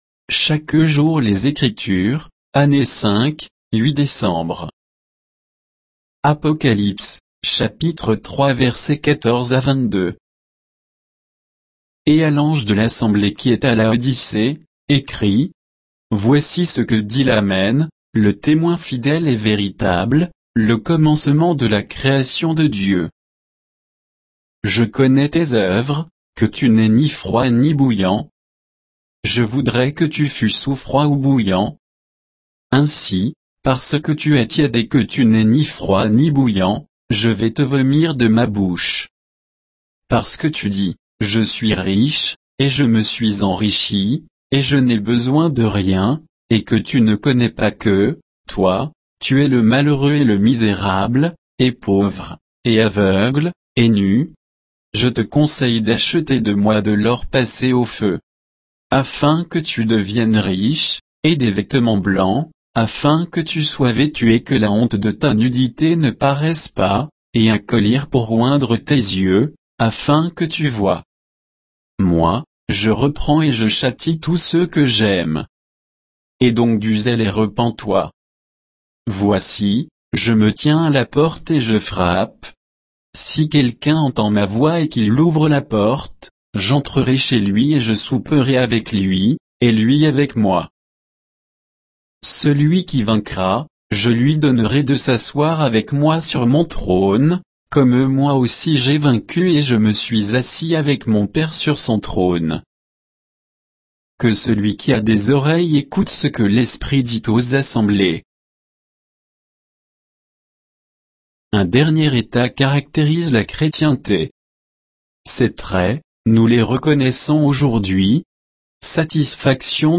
Méditation quoditienne de Chaque jour les Écritures sur Apocalypse 3, 14 à 22